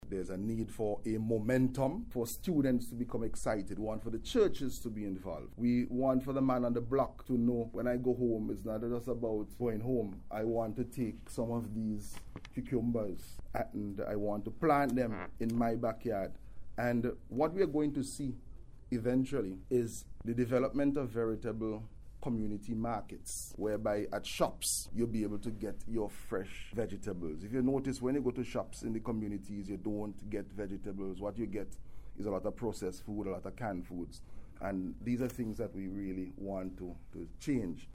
Speaking at a recent ceremony, Caesar pointed to the Zero Hunger Trust fund’s National Home and Community Garden Project which he said can enable the establishment of these markets, once there is widespread involvement of Vincentians in the project.